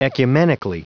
Prononciation du mot ecumenically en anglais (fichier audio)
Prononciation du mot : ecumenically